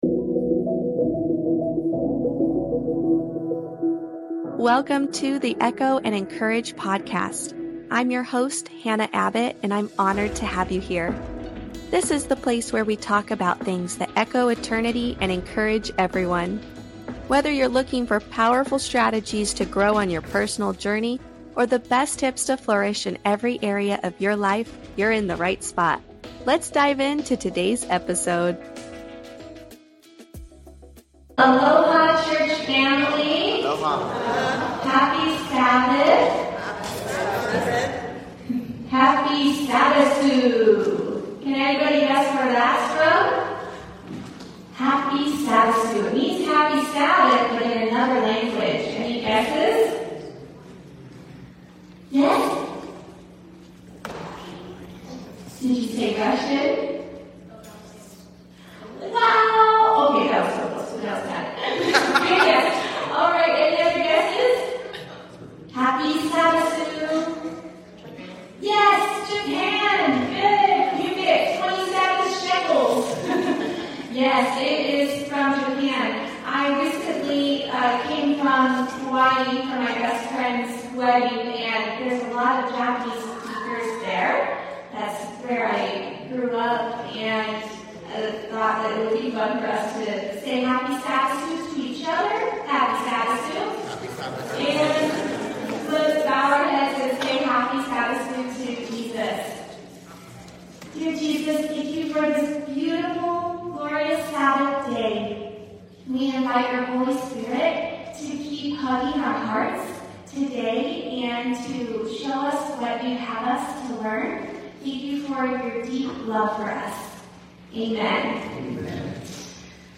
The conversation also touches on the significance of rereading books, recognition in the field, advice for aspiring therapists, and the challenges faced in specialized settings.